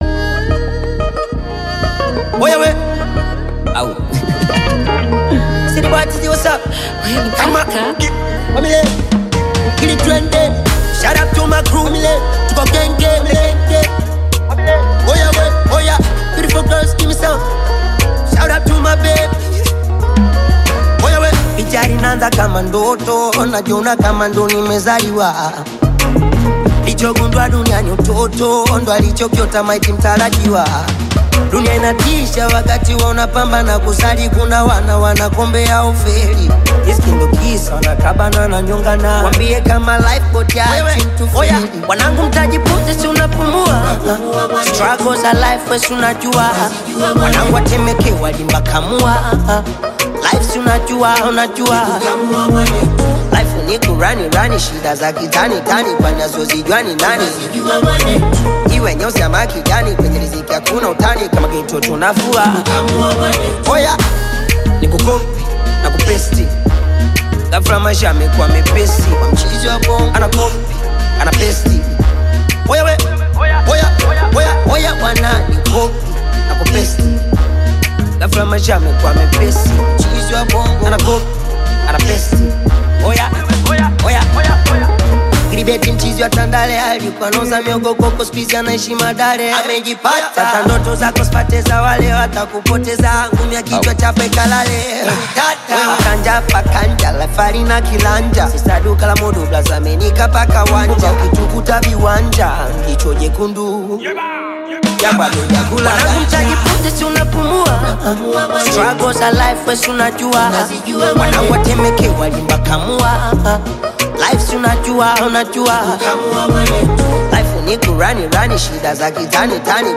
Talented Tanzanian Singer